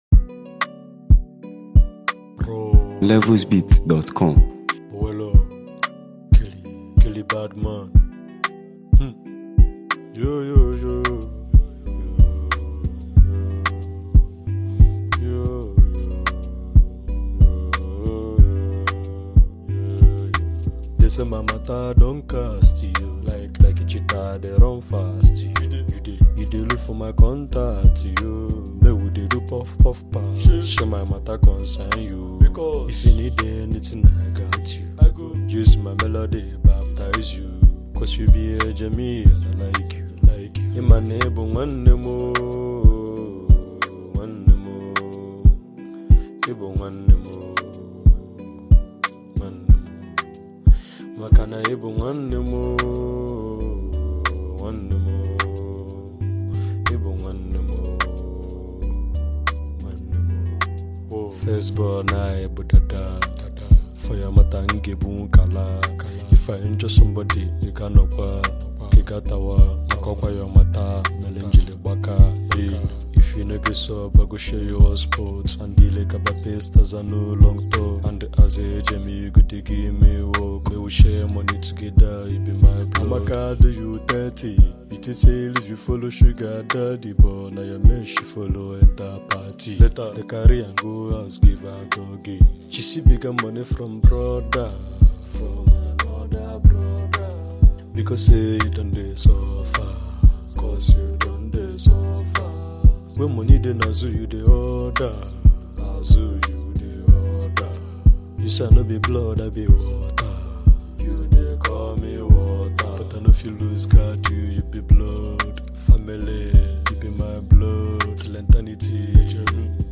Noteworthy Nigerian indigenous rap sensation and lyricist
With his raw delivery and authentic storytelling